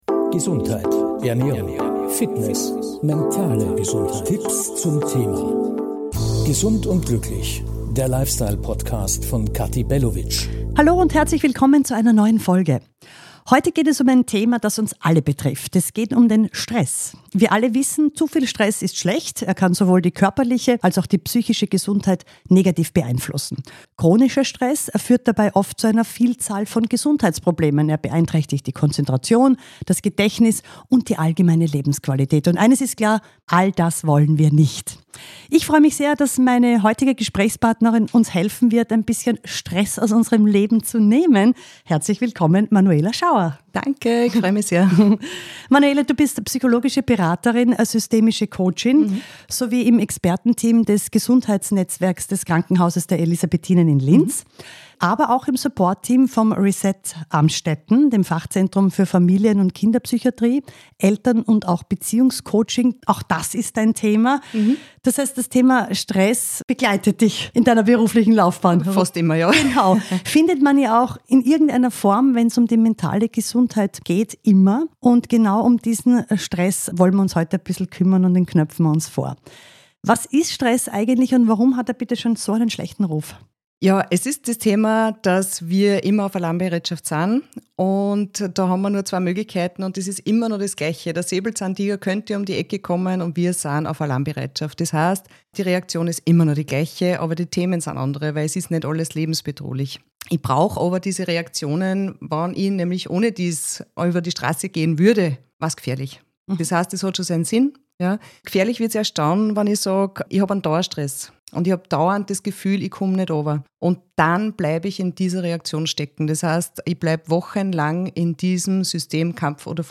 In der heutigen Podcastfolge spreche ich mit der psychologischen Beraterin und systemischen Coachin